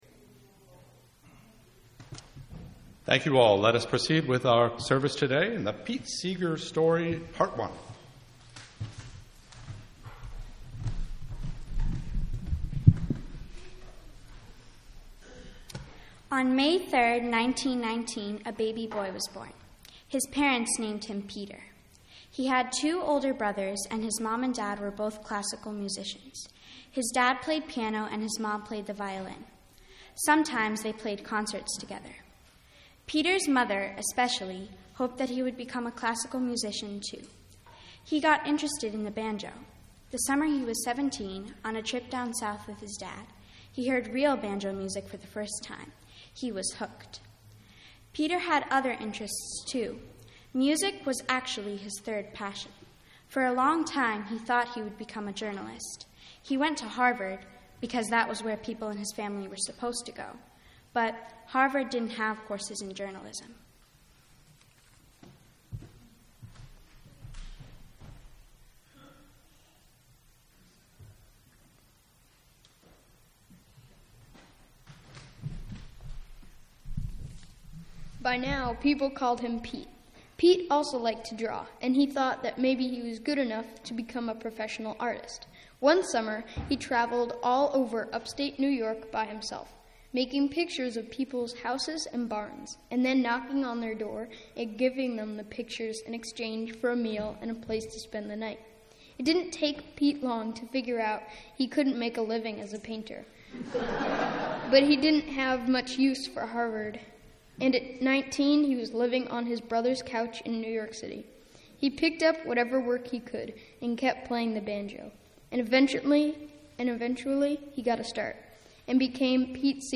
This Sunday is an intergenerational service
The TPC House Band performs and we sing Pete Seeger songs and hear readings about his life.